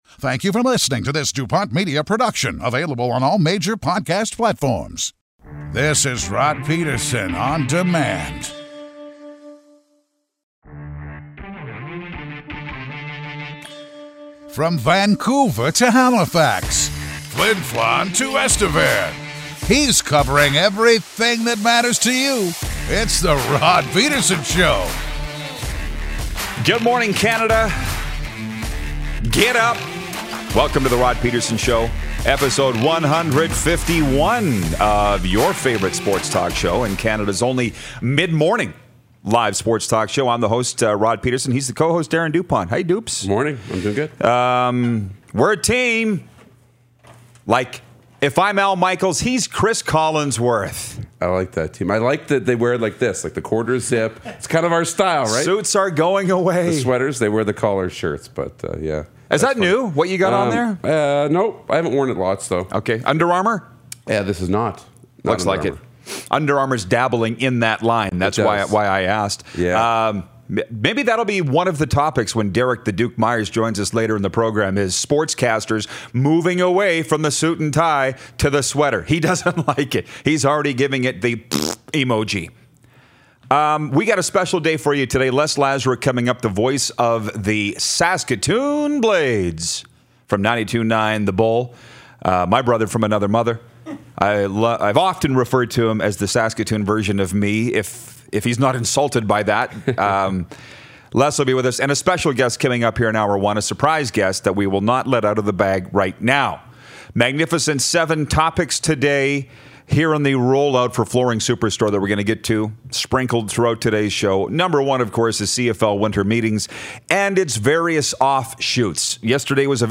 Cody Fajardo, Saskatchewan Roughriders Quarterback calls in!